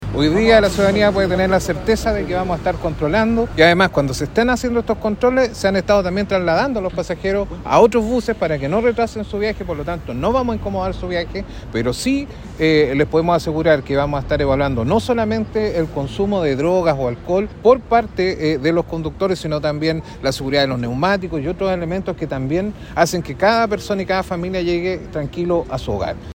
Por su parte, el seremi de Seguridad Pública de La Araucanía, Israel Campusano, afirmó que no solo se detectará el consumo de sustancias, sino que otros elementos como el estado de los microbuses.